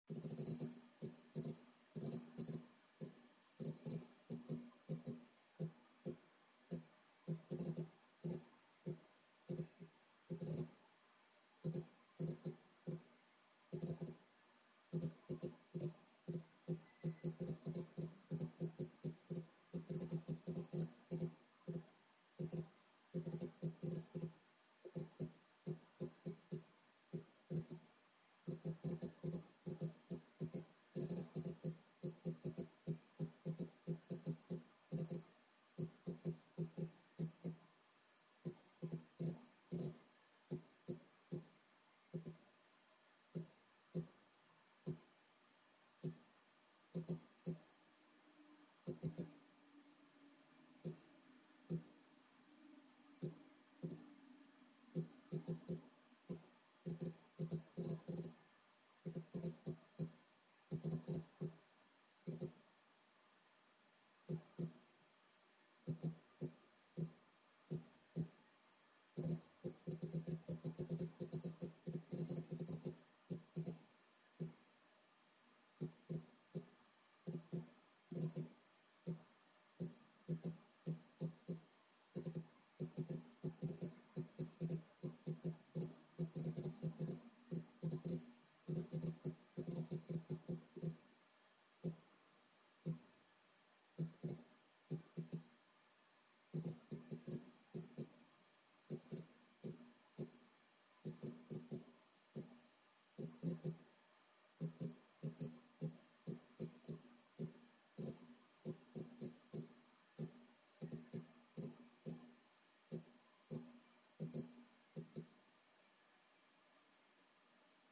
Звуки жесткого диска
Звук наполнения кластеров данными